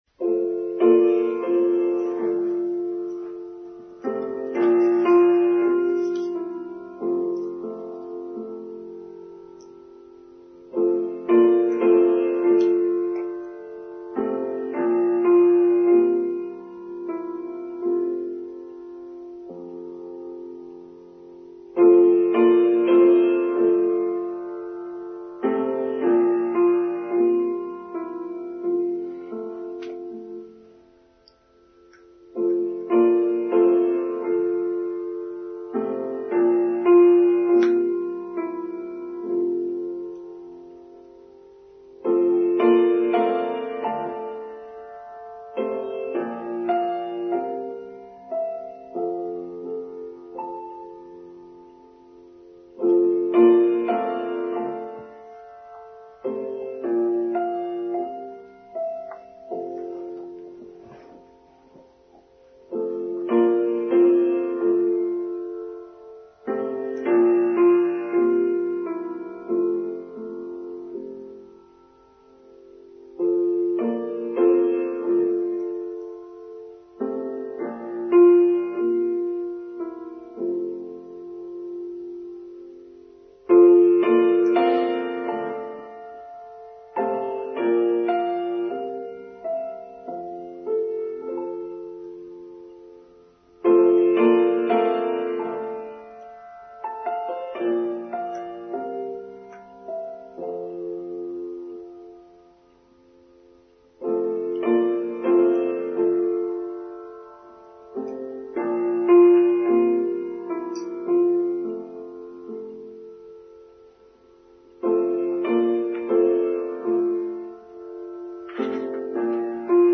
Flexibility: Online Service for Sunday 16th July 2023